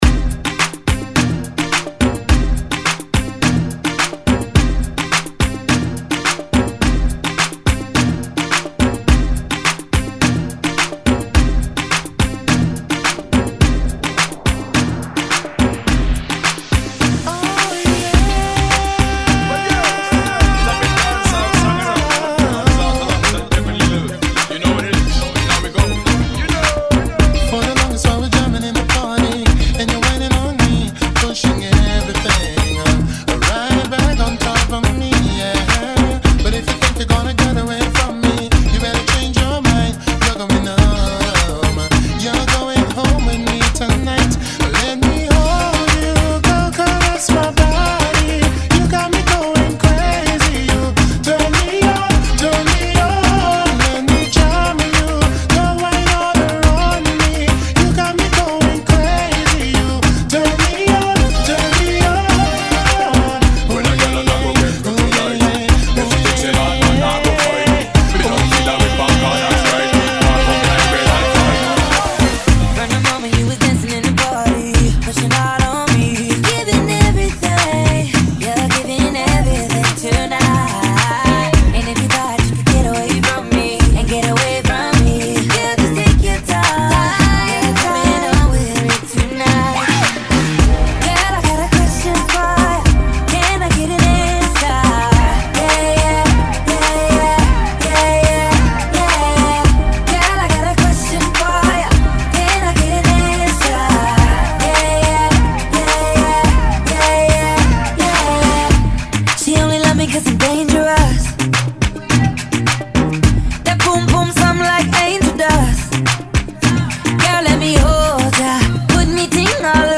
[ 106 Bpm ]